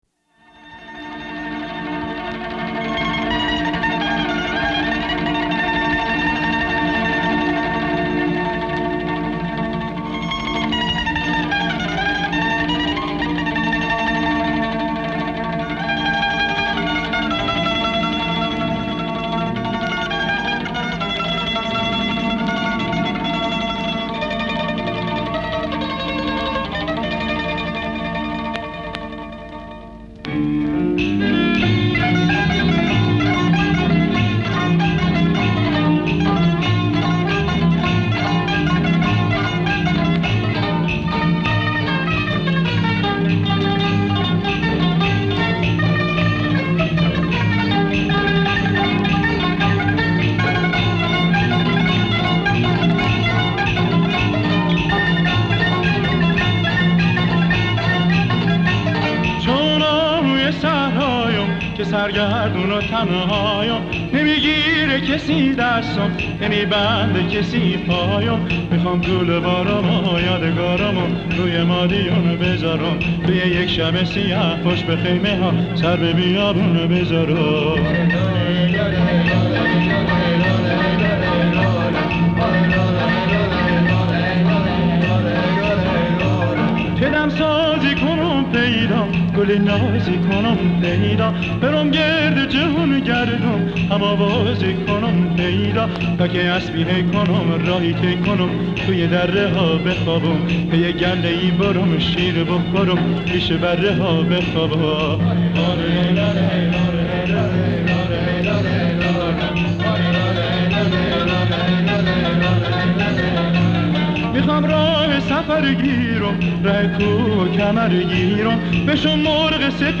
در مقام شور